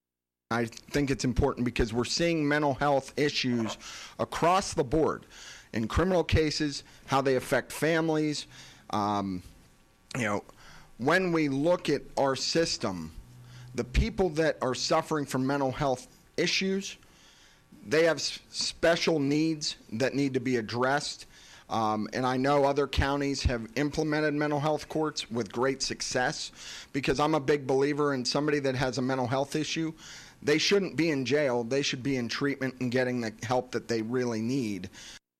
The three candidates for Indiana County Court of Common Pleas met at Renda Broadcasting and Digital’s Studios this morning to discuss several issues related to their position.